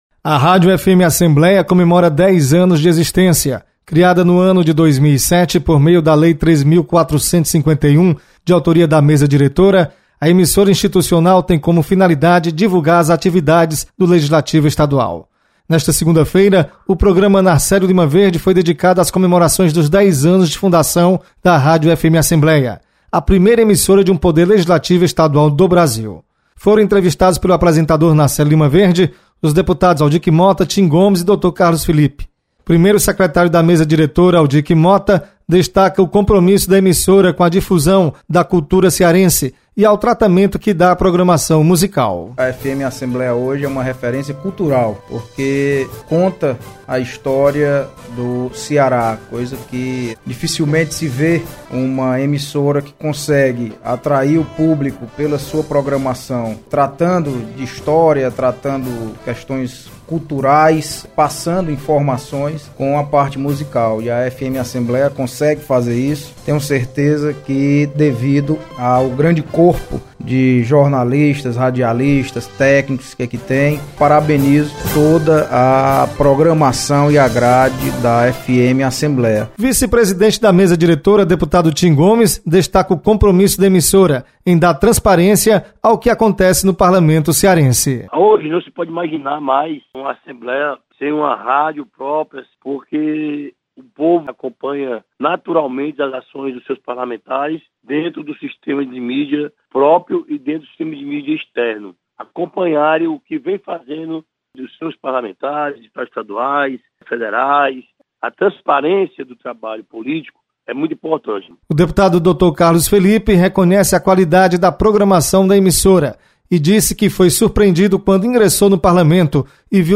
Deputado Zezinho Albuquerque destaca os 10 anos da Rádio FM Assembleia.